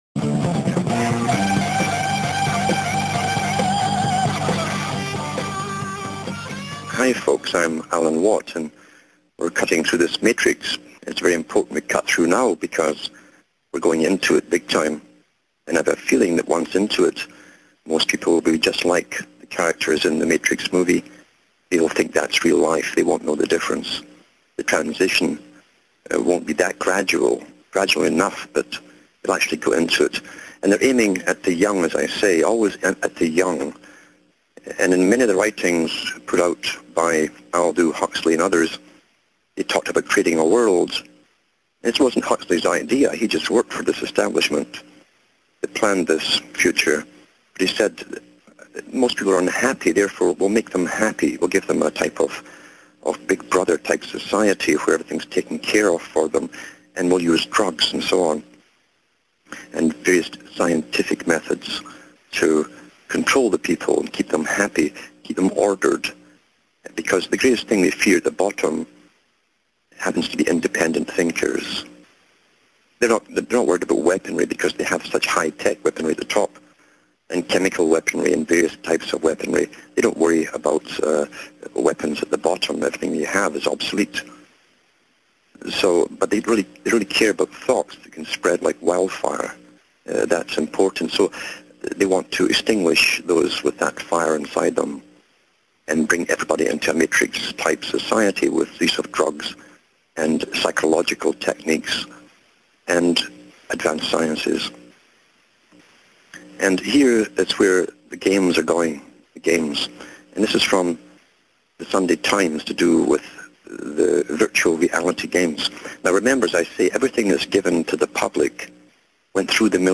LIVE on RBN